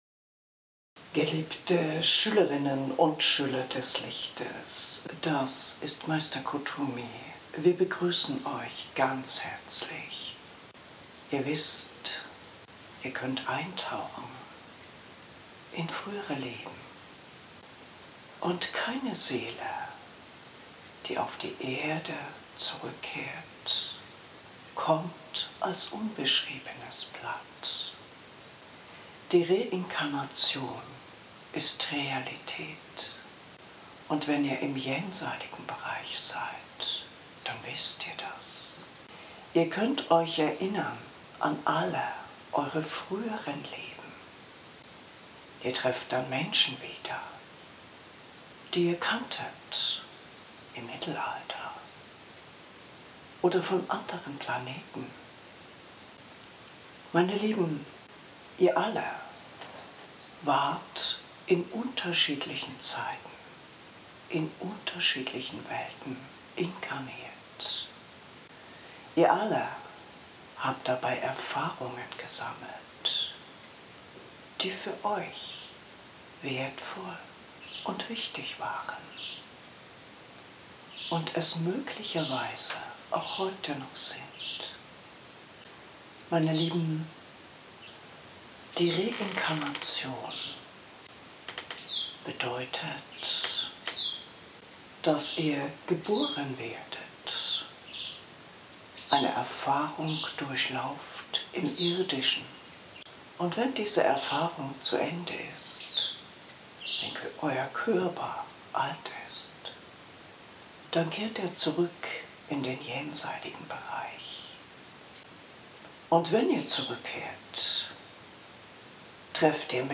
Hörprobe Lichtvolle Botschaften 1 Reinkarnation, Karma, Streichung aus der Bibel und Aufstieg ...